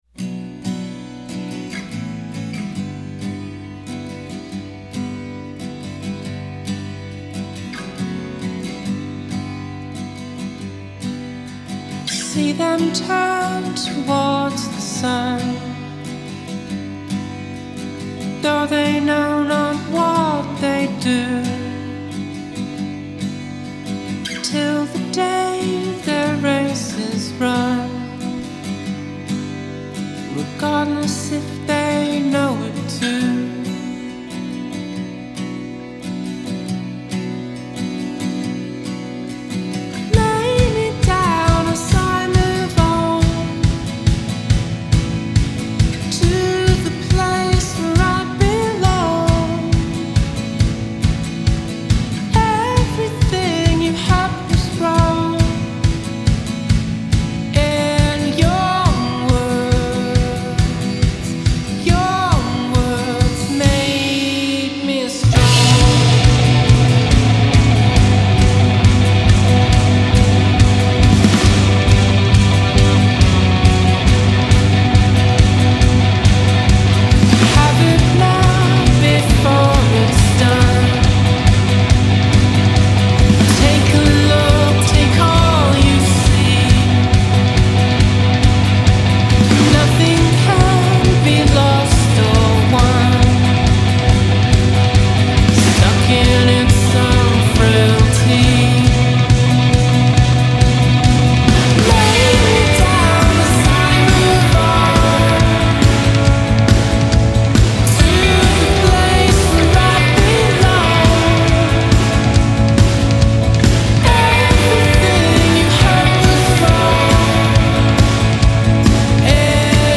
Sydney four-piece